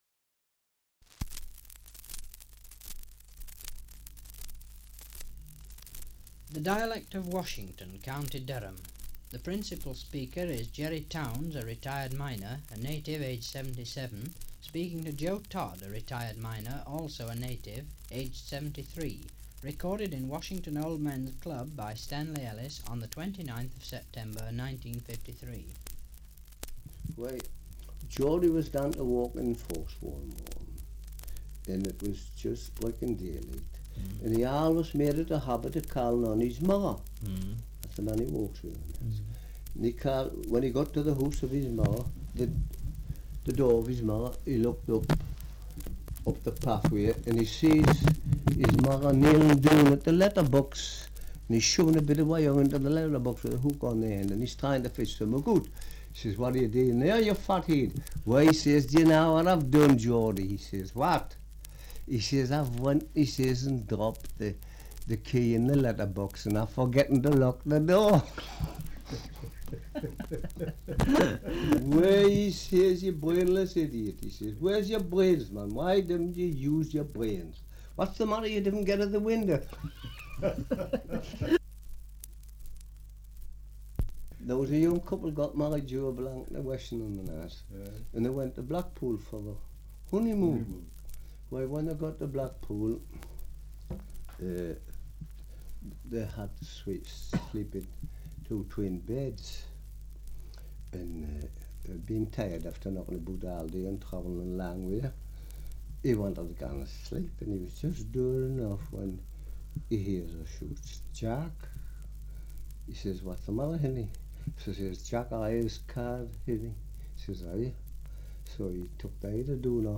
1 - Survey of English Dialects recording in Washington, Co. Durham
78 r.p.m., cellulose nitrate on aluminium